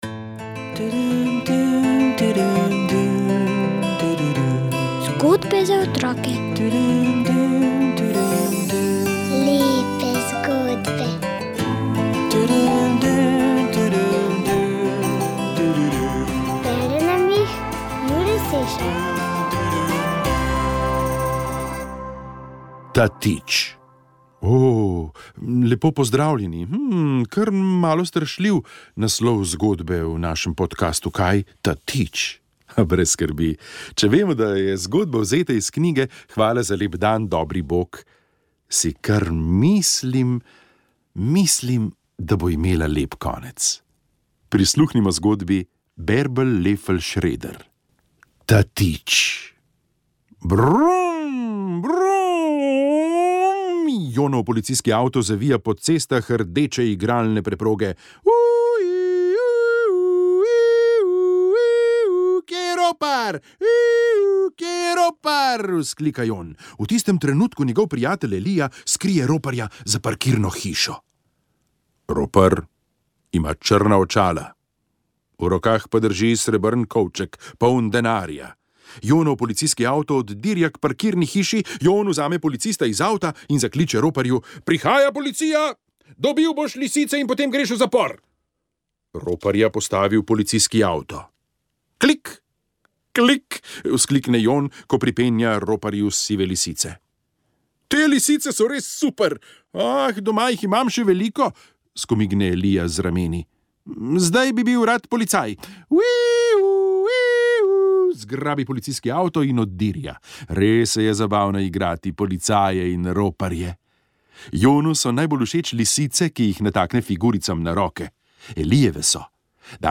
otroci otrok zgodbe zgodba pravljica pravljice Hvala za lep dan dobri Bog